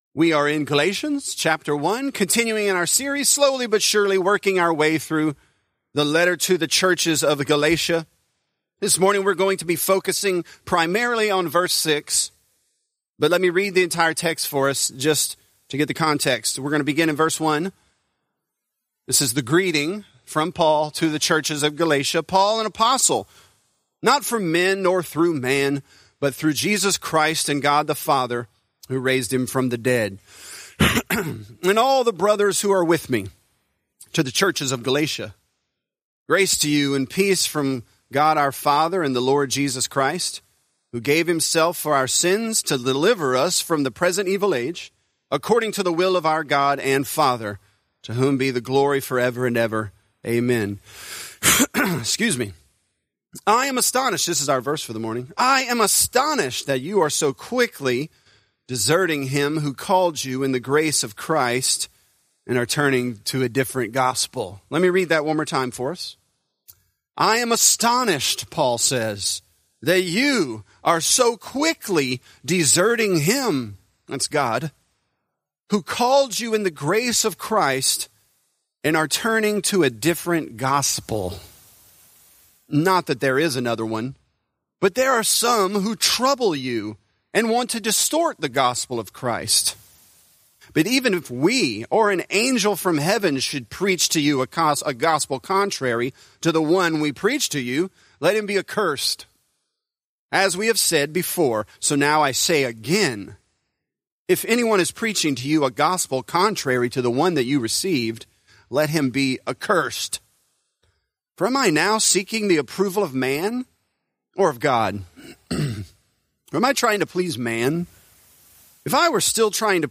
Galatians: Desertion & Defection | Lafayette - Sermon (Galatians 1)